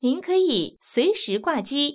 ivr-you_may_exit_by_hanging_up.wav